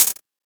Closed Hats
edm-hihat-57.wav